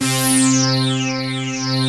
BIG LEADC3-L.wav